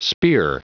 Prononciation du mot spear en anglais (fichier audio)
Prononciation du mot : spear